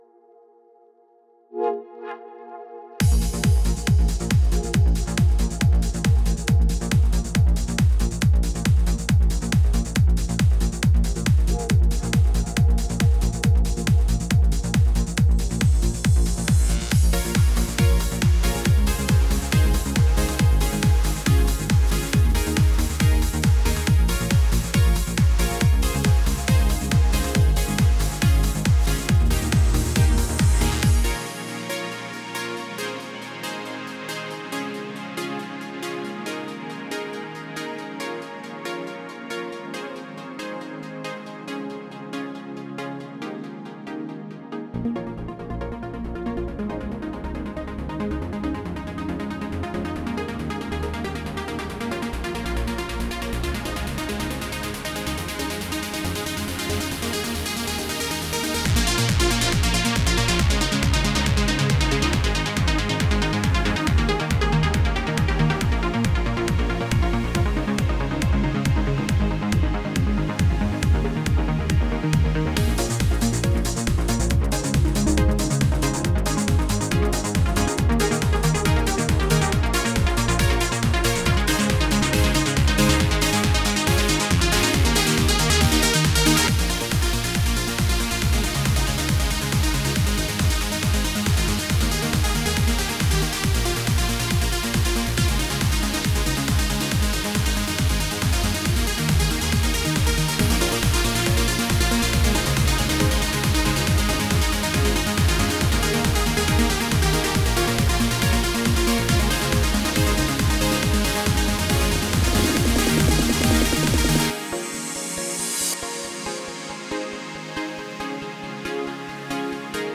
Trance Транс музыка